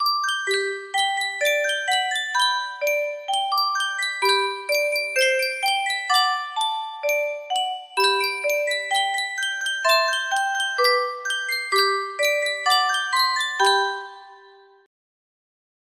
Yunsheng Music Box - Were You There 6287 music box melody
Full range 60